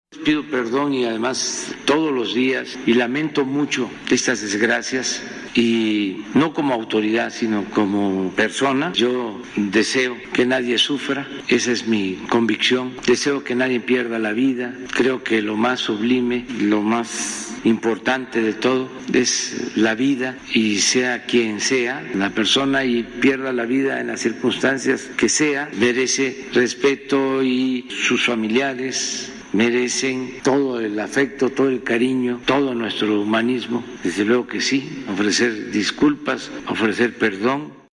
En la conferencia de prensa matutina en Palacio Nacional se le preguntó si así como pidió perdón a la comunidad china en México lo hará también con los afectados por el suceso en el Metro o tendrá un acercamiento con ellos, aunque no sea – como lo rechazó hace unos días- irse a tomar una foto.